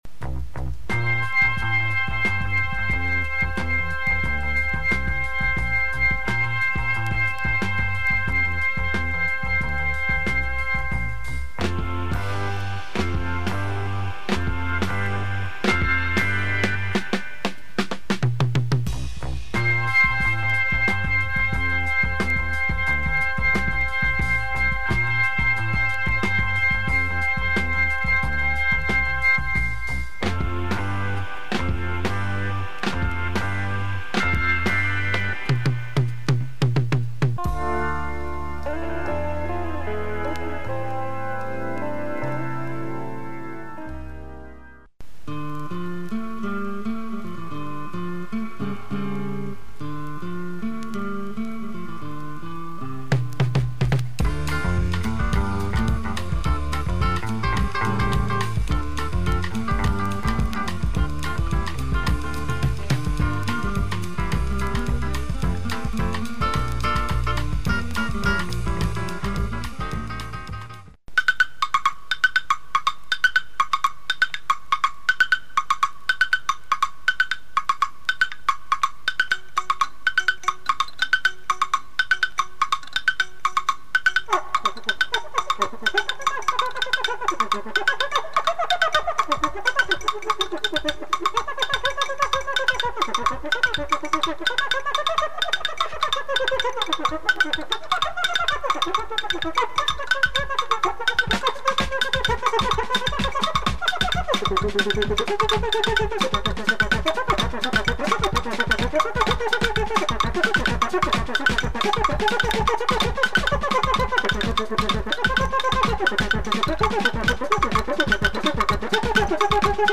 A very nice François de Roubaix flavour on this record.